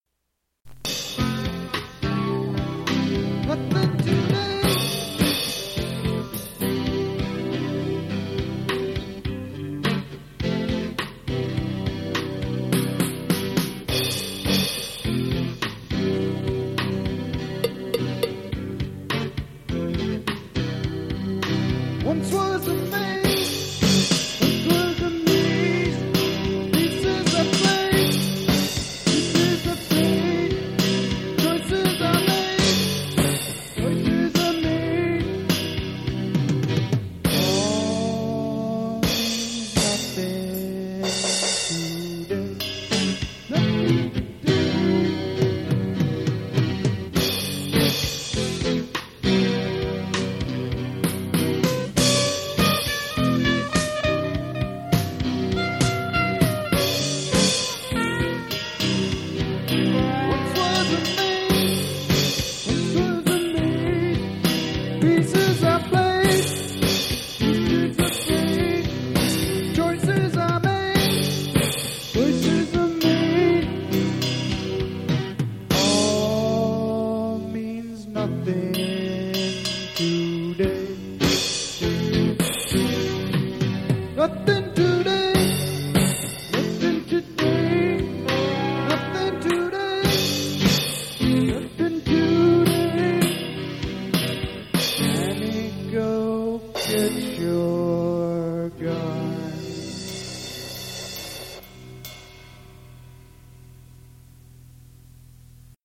fostex 4 track.
recording cut off. quiet.
Recently I purchased a USB cassette to play this music and convert to MP3.
They are mostly rehearsals and it certainly shows in some instances.
We recorded our rehearsals using a single microphone in the center of the room and a double cassette deck.
Everything was a single take.